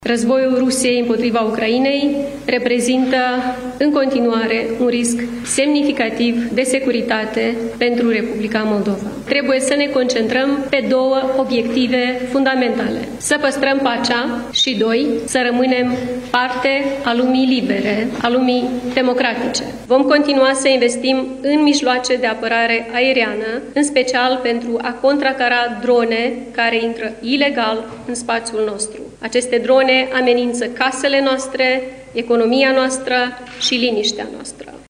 Aceasta a prezentat într-o conferință de presă prioritățile președinției în acest an.